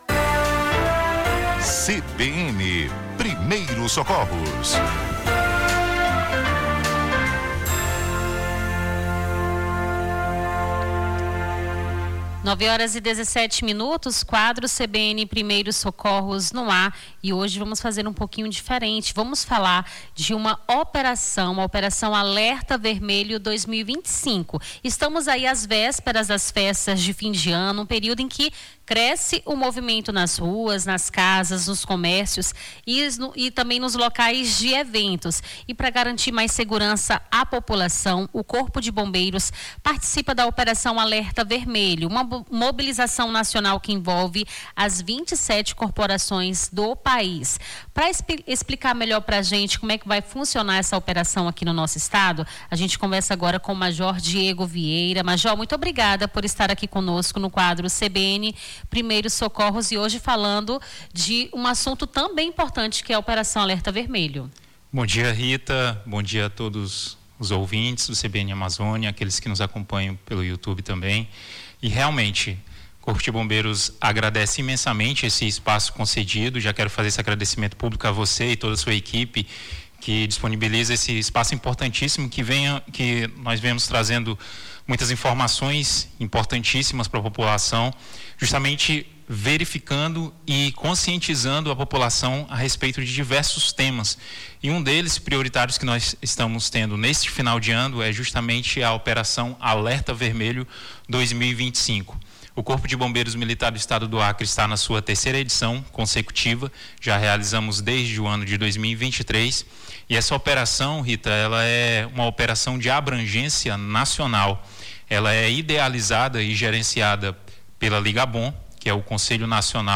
Nesta quinta-feira, 27, recebemos em nosso estúdio